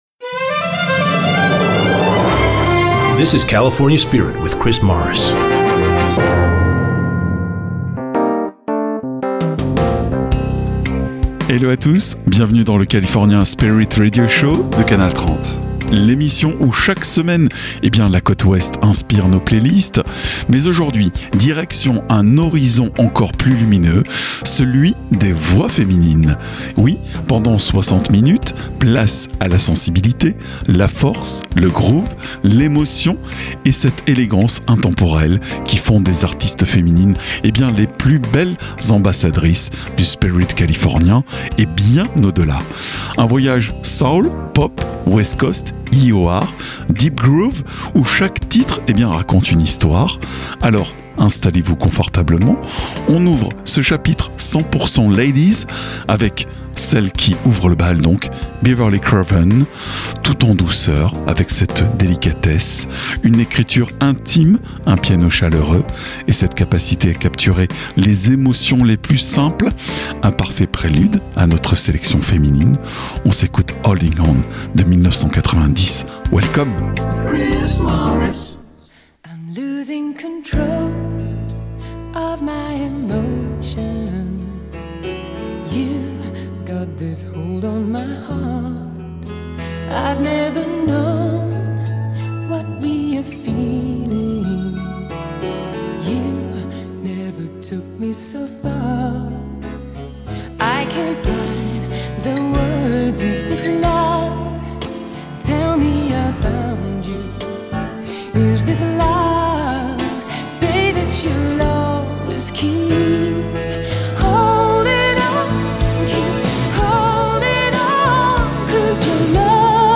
Le California Spirit est un concept unique inspiré des radios US , avec des PowersPlays (nouveautés) et ExtraGold (Oldies).
C’est un format musique californienne (Allant du Classic Rock en passant par le Folk, Jazz Rock, Smooth jazz) le tout avec un habillage visuel très 70’s et un habillage sonore Made in America. Tout cela forme l’AOR music (Album Oriented rock) qui naissait au milieu des années 70 par des djays américains.